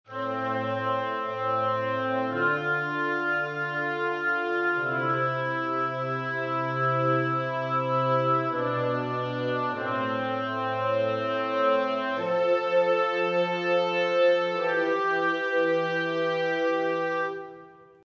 Man kann die Stimmen auch aufteilen und die Instrumente über zwei MIDI-Kanäle ansprechen: